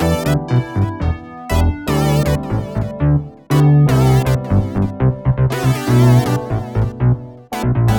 34 Backing PT1.wav